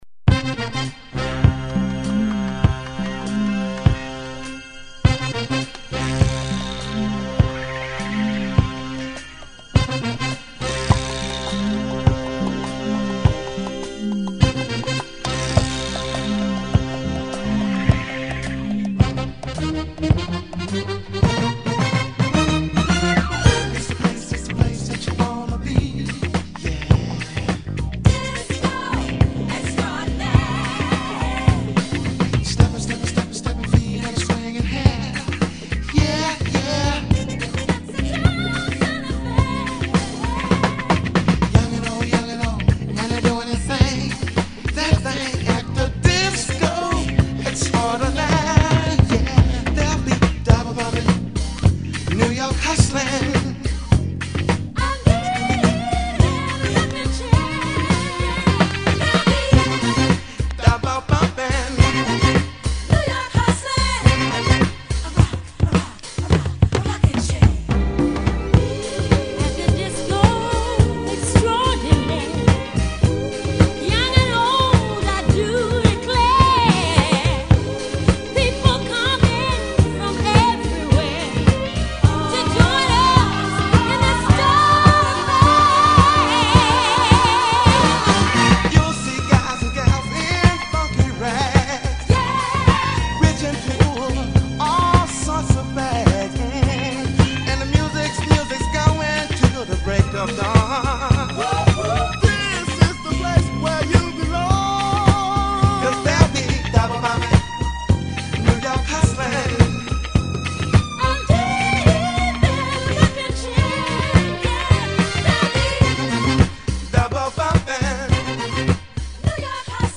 [ DISCO | FUNK | SOUL ]